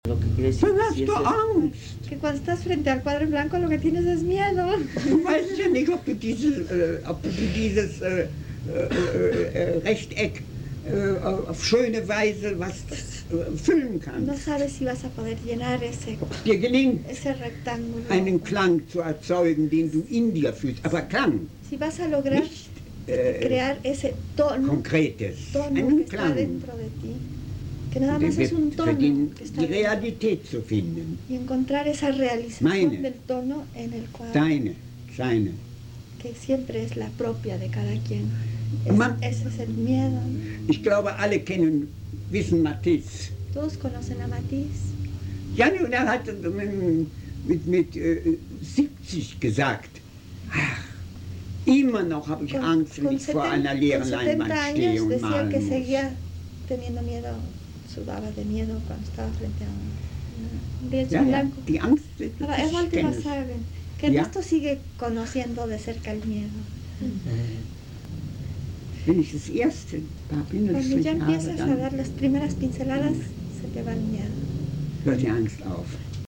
Las grabaciones se realizaron en el taller de pintura con jóvenes del barrio de Tepito, en la Casa de Cultura Enrique Ramírez y Ramírez, en el año de 1984.